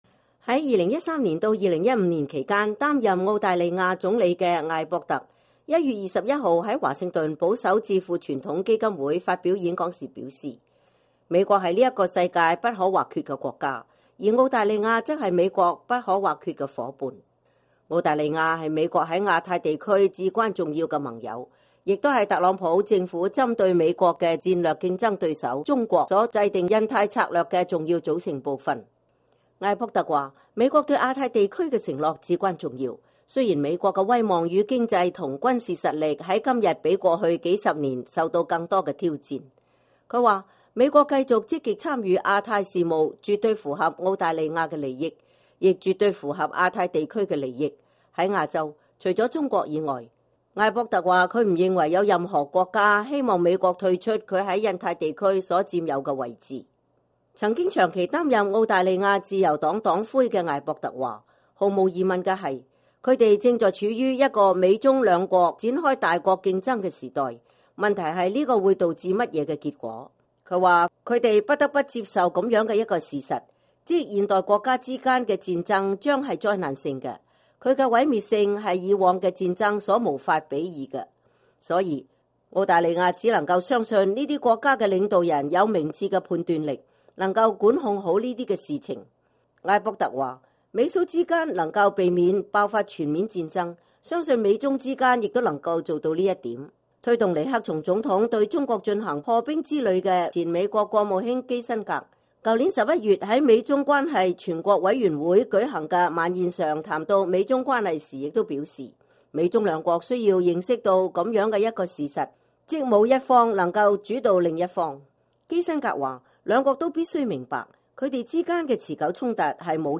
前澳大利亞總理艾博特1月21日在傳統基金會發表演講。
在2013到2015年期間擔任澳大利亞總理的艾博特(Tony Abbott)1月21日在華盛頓保守智庫傳統基金會發表演講時表示，美國是這個世界不可或缺的國家，而澳大利亞則是美國不可或缺的伙伴。